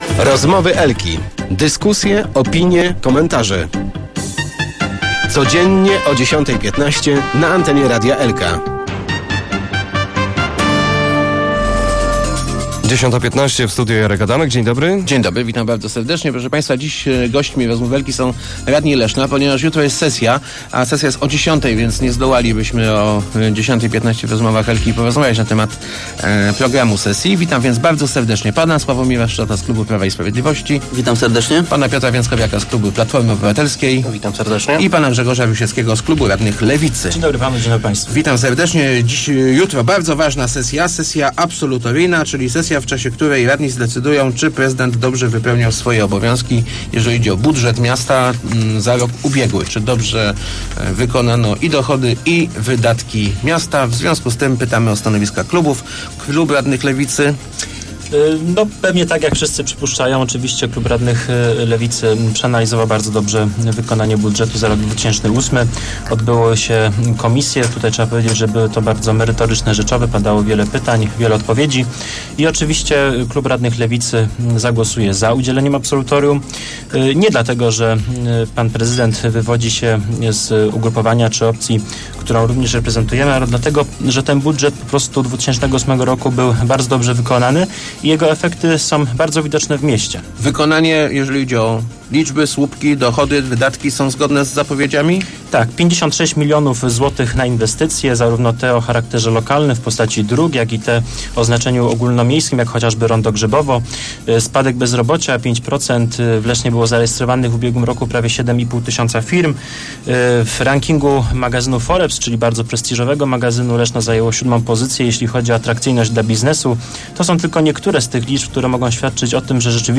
Tomasz Malepszy nie ma szans na jednogłośne absolutorium Rady Miejskiej. Platforma Obywatelska oraz Prawo i Sprawiedliwość będą głosować przeciw – zapowiedzieli w Rozmowach Elko przedstawiciele klubów Piotr Więckowiak i Sławomir Szczot.